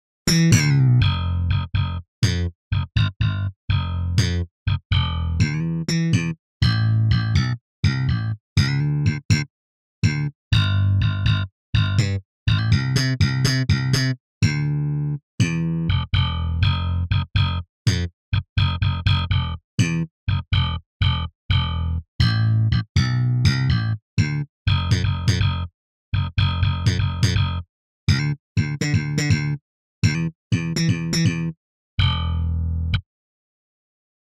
P Bass Slap